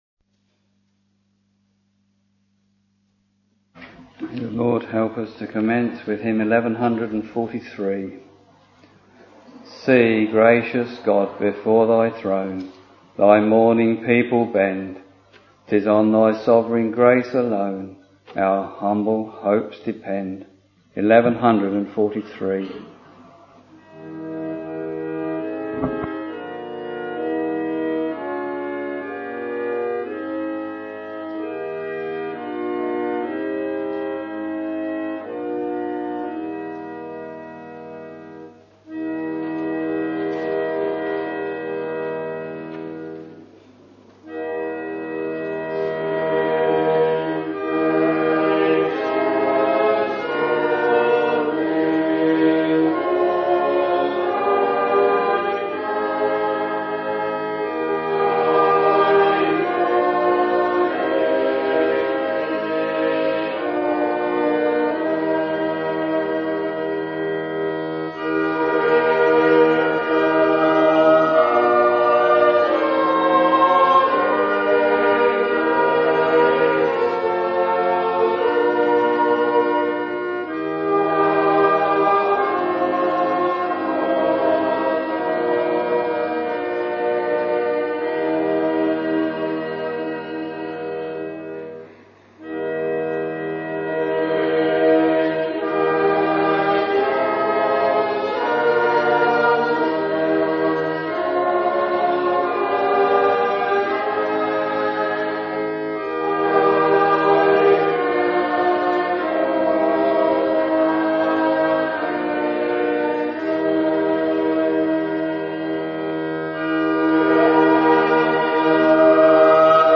Friday, 17th September 2010 — Week Evening Service Preacher